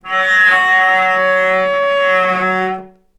healing-soundscapes/Sound Banks/HSS_OP_Pack/Strings/cello/sul-ponticello/vc_sp-F#3-ff.AIF at b3491bb4d8ce6d21e289ff40adc3c6f654cc89a0
vc_sp-F#3-ff.AIF